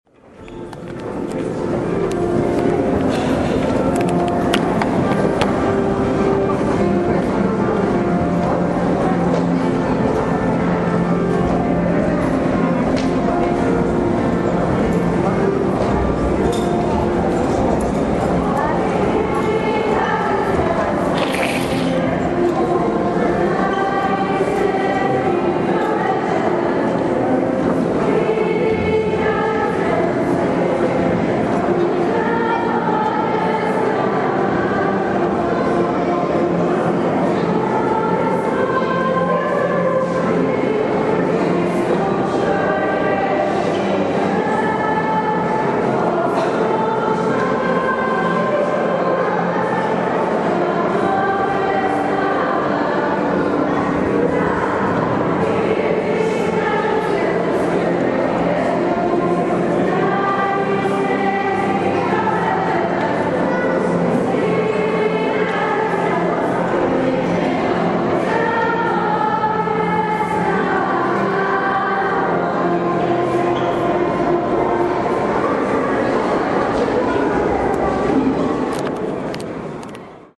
ZBOR DJECE i MLADIH – AUDIO:
završna pjesma – ZBOR DJECE i MLADIH